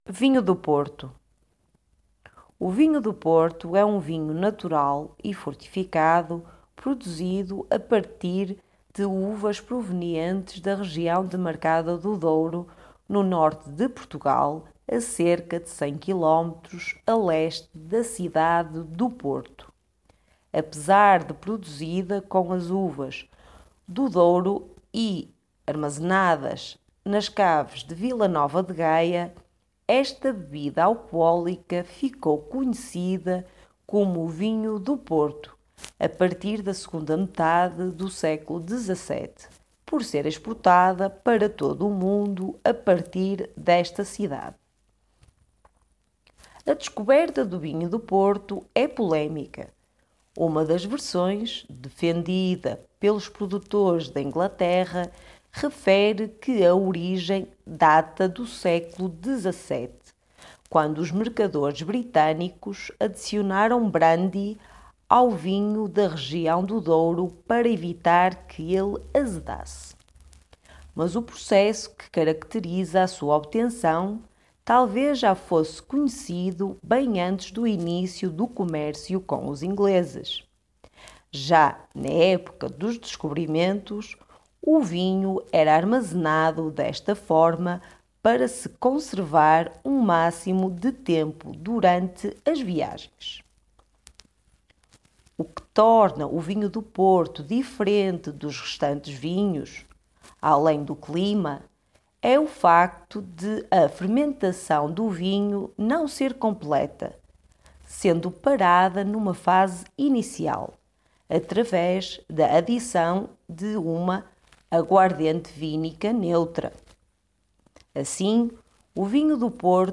Our article text and audio are specifically aimed towards the Portuguese language from Portugal, and not from Brazil or other Portuguese speaking countries.